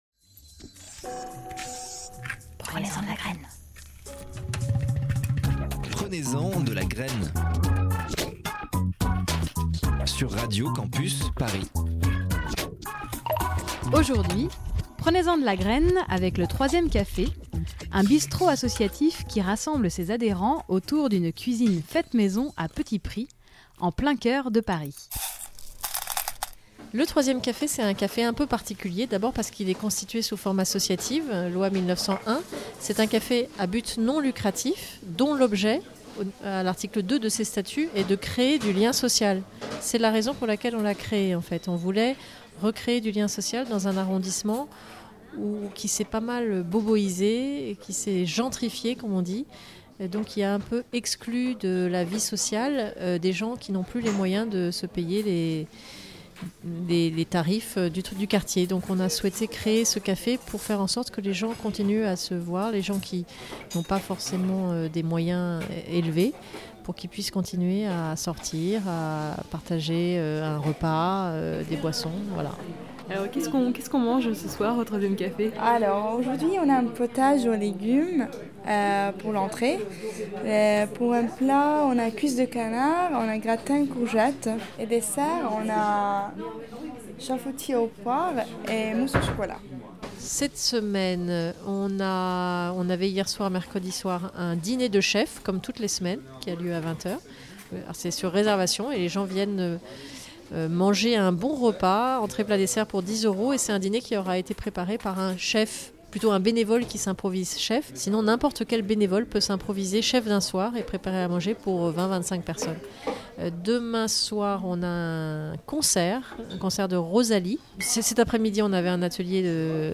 Interview et montage